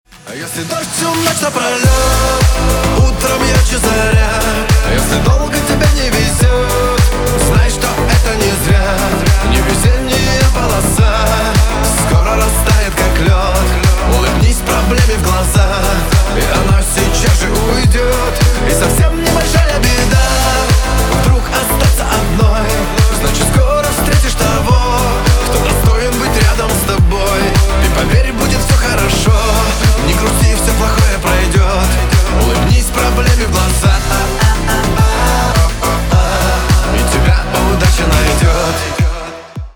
Веселый русский рингтон